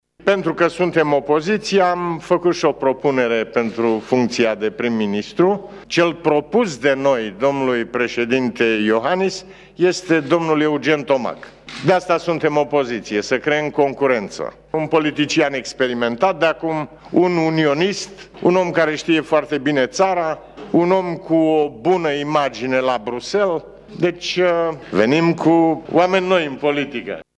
Întrebat de ce PMP nu l-a nominalizat pe el pentru această funcție, fostul președinte Băsescu a spus că este nevoie de un om tânăr în fruntea Guvernului: